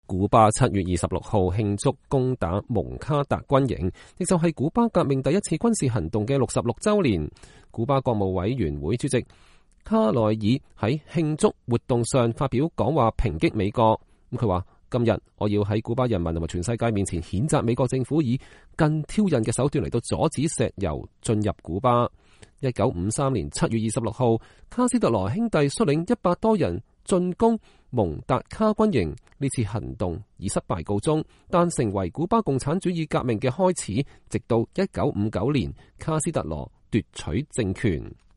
古巴國務委員會主席迪亞斯·卡內爾在慶祝活動上發表講話抨擊美國：“今天我要在古巴人民和全世界面前譴責美國政府以更挑釁的手段阻止石油進入古巴”。